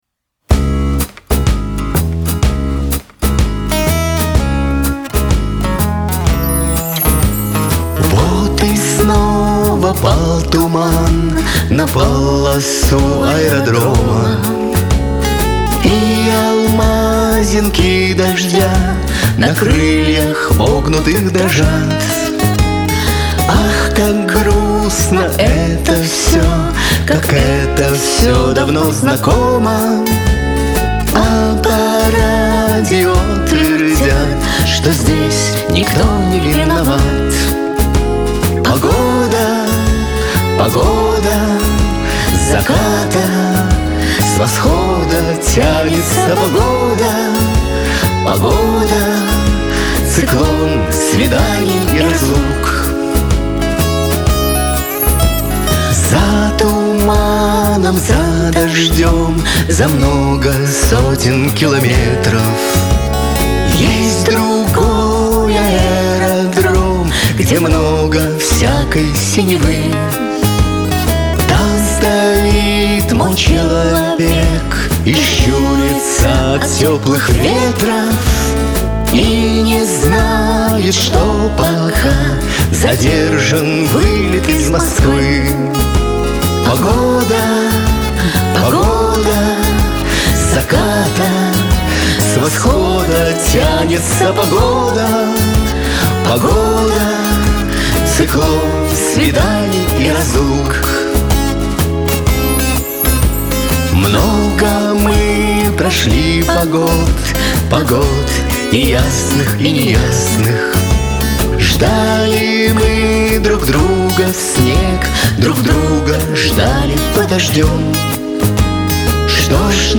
Шансон
Лирика
эстрада